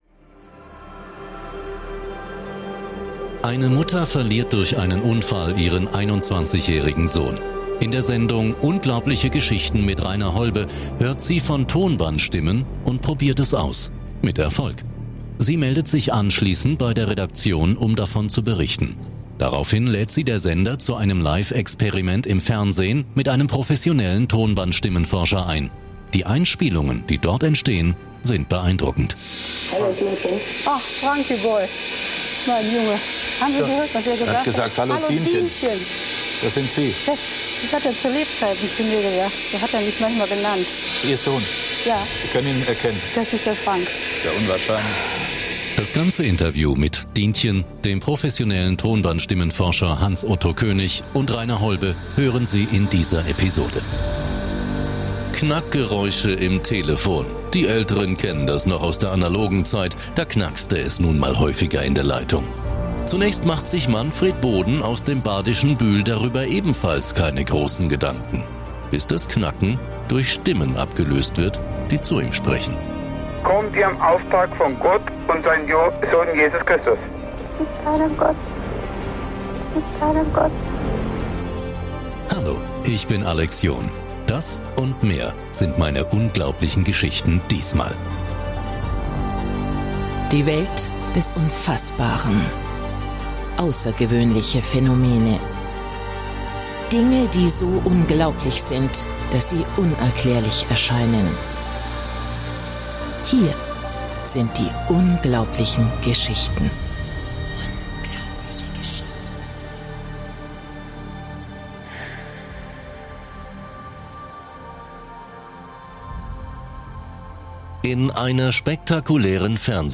In dieser Episode hören Sie die Originalaufnahmen.